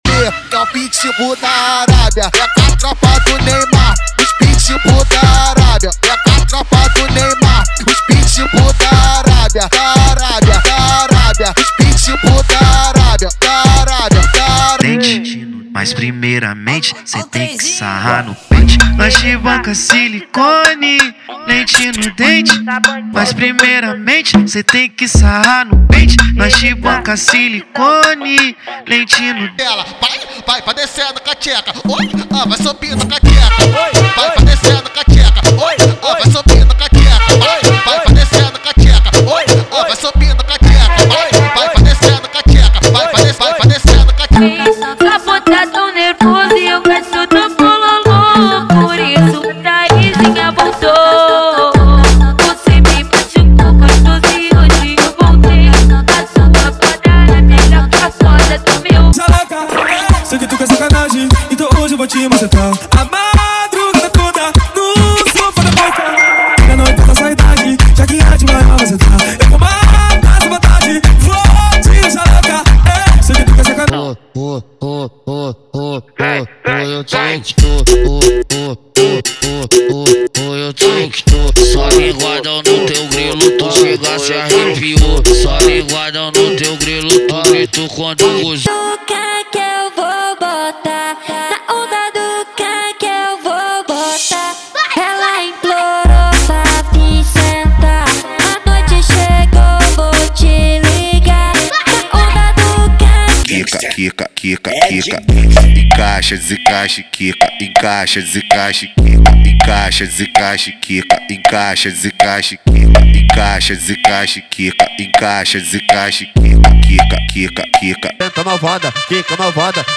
• Funk Proibidão, Funk Rave e Funk Mandelão = 125 Músicas
• Sem Vinhetas